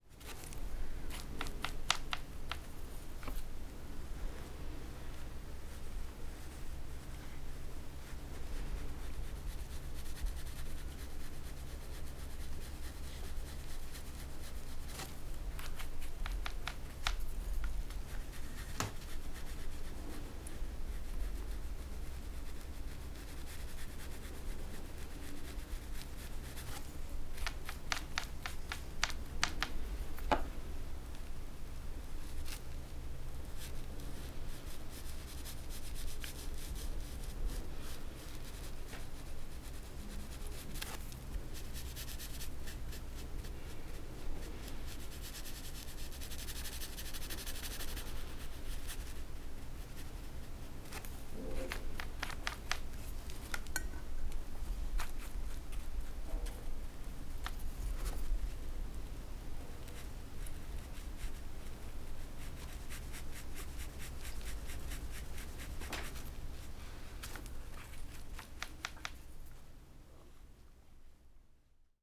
Strasbourg, COS11 / Explorer100 / KM150
peinture.mp3